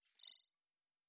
Sci-Fi Sounds / Weapons
Additional Weapon Sounds 1_5.wav